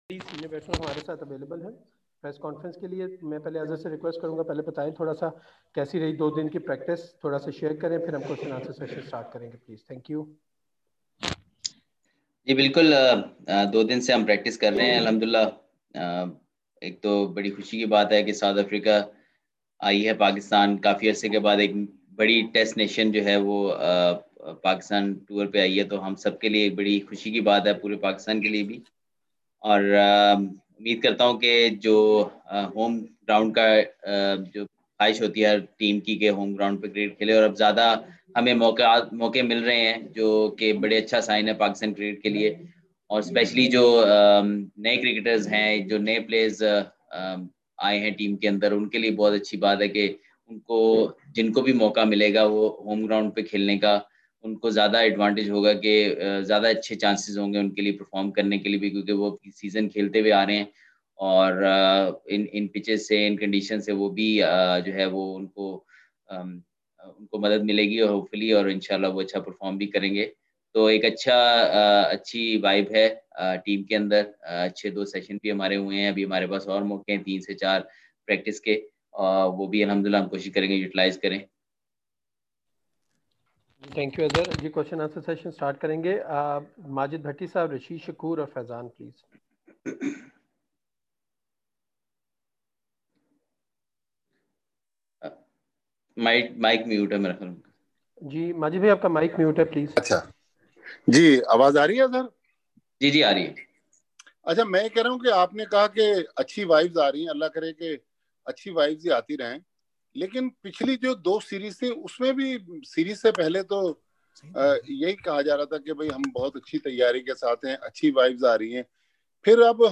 Azhar Ali interacted with media via videoconference call today, ahead of the first Test match against South Africa starting from 26 January at the National Stadium Karachi.